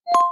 Звук включения голосового ассистента Яндекс Алиса